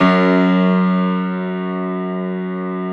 53d-pno04-F0.wav